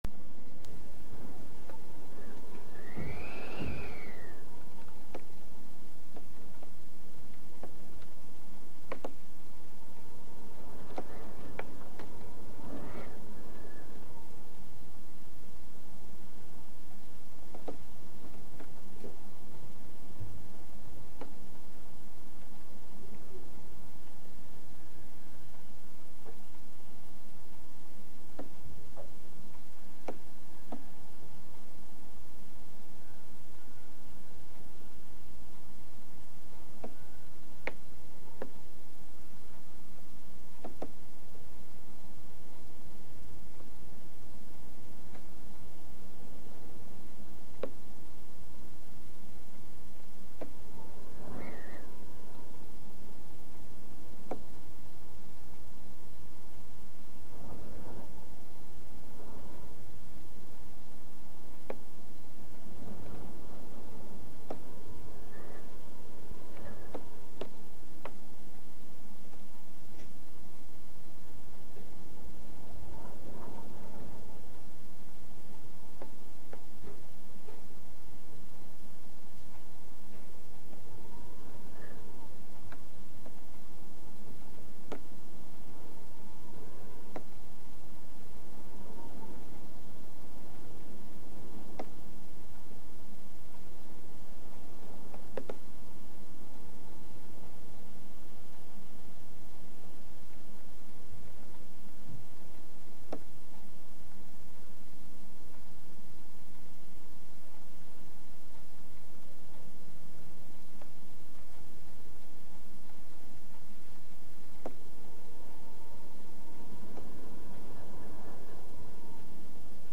More Whistling Wind and Rain yesterday